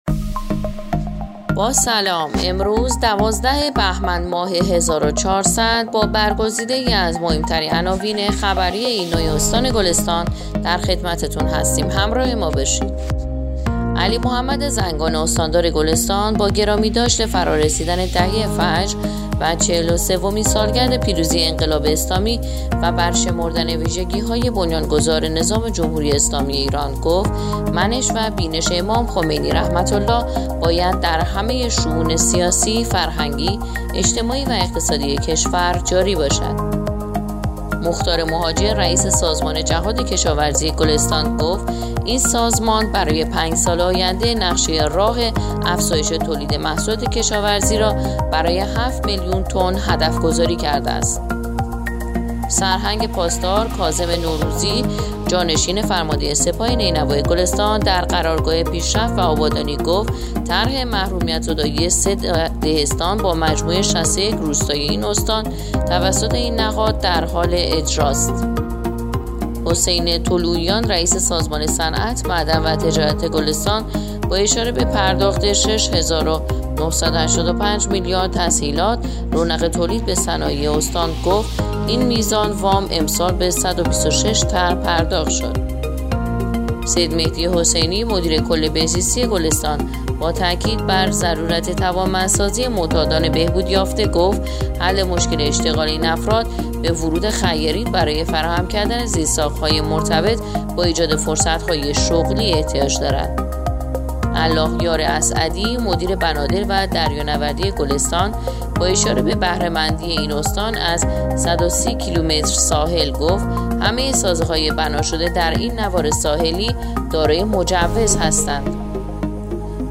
پادکست/ اخبار شبانگاهی دوازدهم بهمن ماه ایرنا گلستان